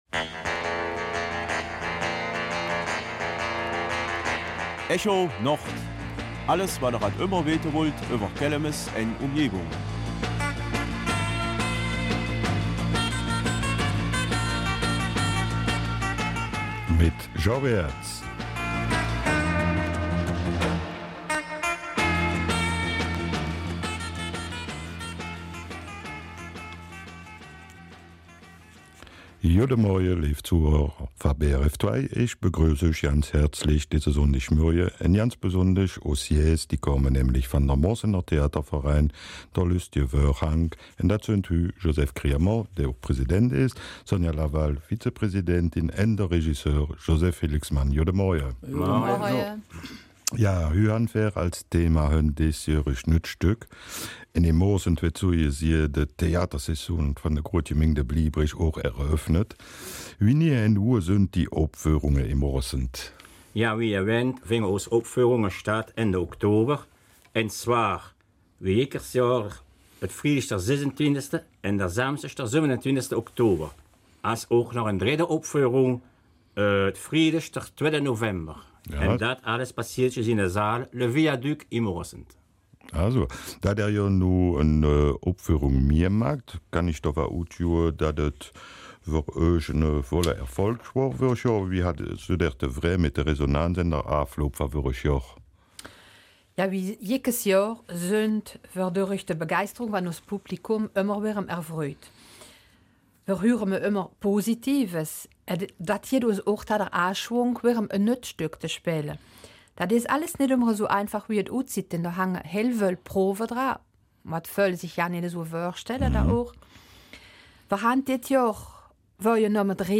In der Großgemeinde Plombières wird die Saison der Mundarttheaterstücke wieder eröffnet mit dem Moresneter Theaterverein ''Der Lösteje Vörhang''. Als Studiogäste